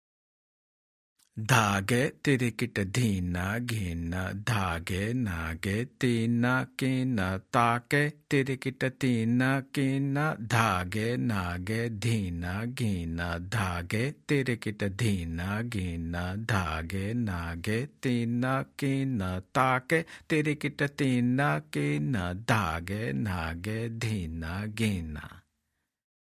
Demonstrations
1x Speed (slow) – Spoken